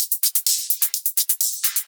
Index of /VEE/VEE2 Loops 128BPM
VEE2 Electro Loop 302.wav